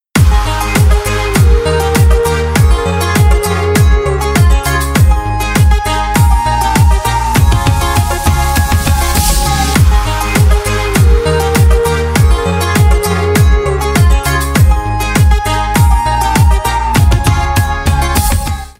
• Качество: 320, Stereo
гитара
красивые
мелодичные
Electronic
без слов